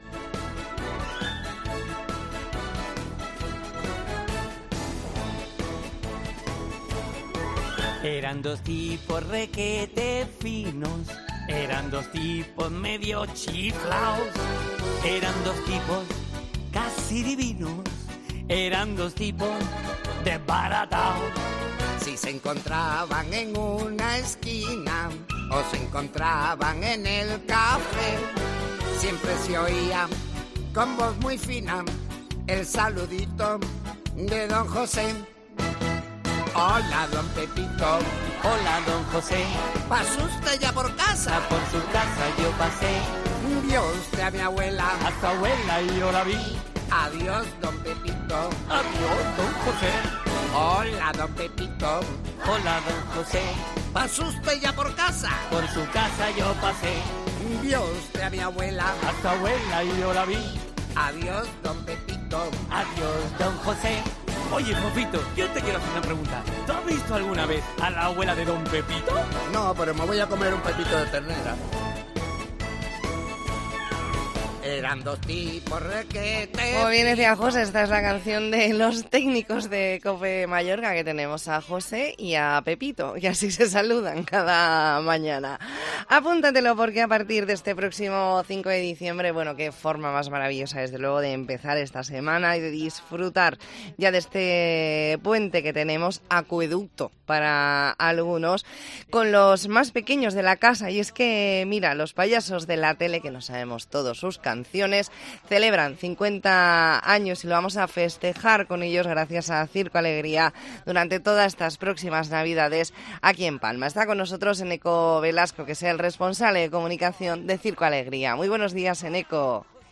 Entrevista en La Mañana en COPE Más Mallorca, lunes 4 de diciembre de 2023.